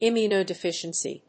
音節im・mu・no・de・fi・cien・cy 発音記号・読み方
/ìmjʊnoʊdɪfíʃənsi(米国英語), ˌɪmju:nəʊˌdɪˈfɪʃʌnsi:(英国英語)/